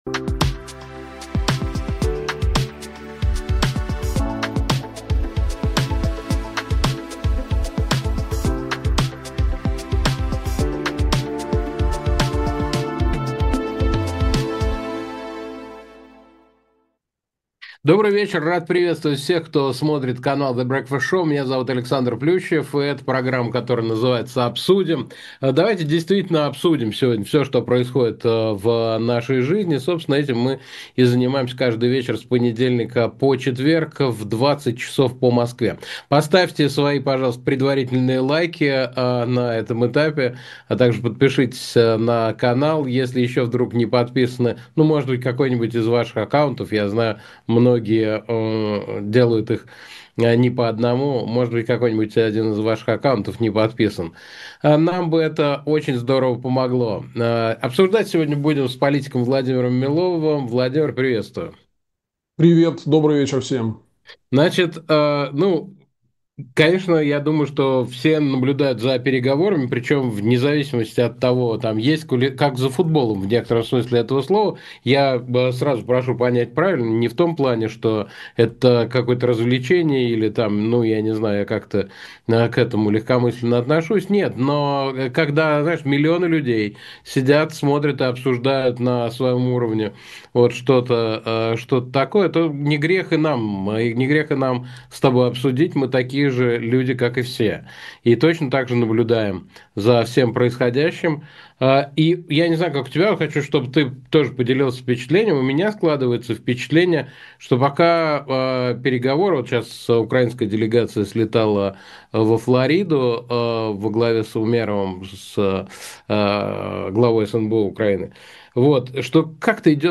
Эфир ведёт Александр Плющев
Гость сегодняшнего выпуска — политик Владимир Милов. Обсудим с ним, что сейчас происходит с переговорами, как объясняют блокировку WhatsApp в РФ и как Украина лишает Россию нефтяных доходов.